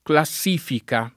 [ kla SS& fika ]